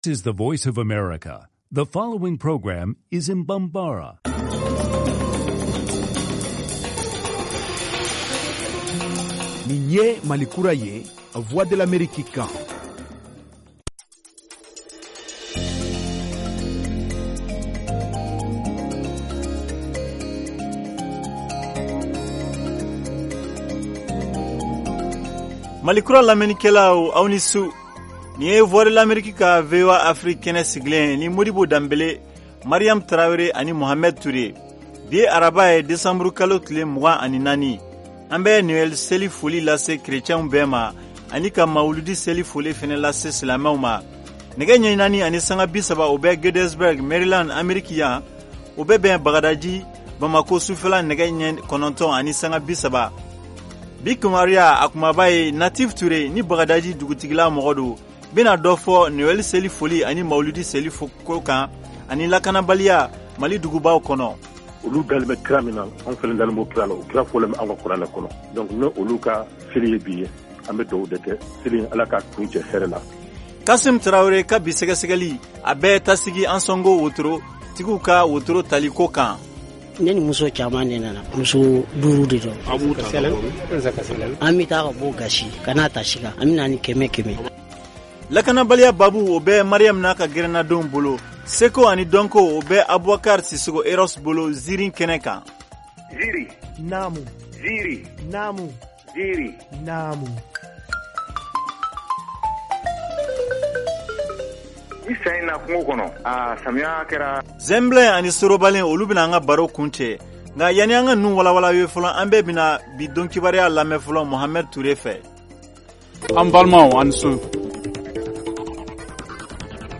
Emission quotidienne en langue bambara
en direct de Washington, DC, aux USA